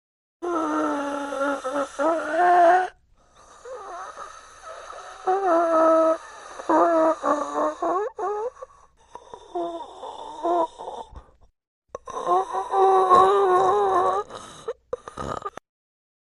Fnaf 1 Bonnie&Chica Breathing sound effects free download